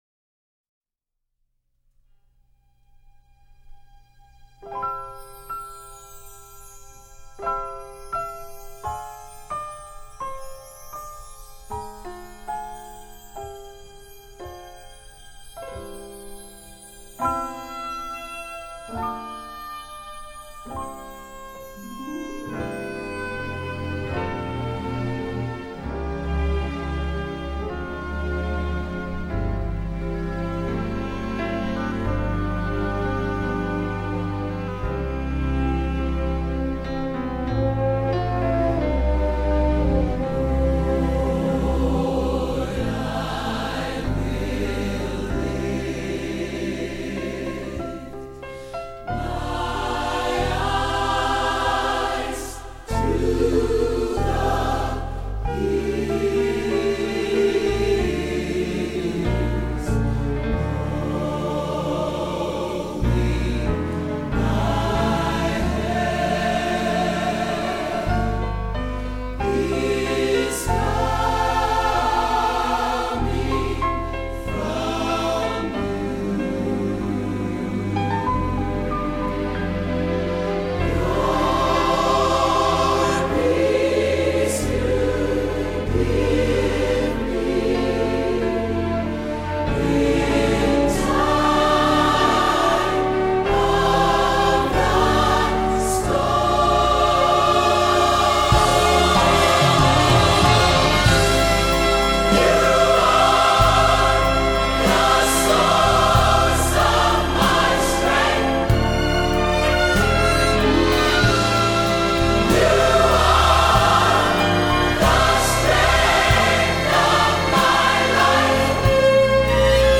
Upcoming anthem recordings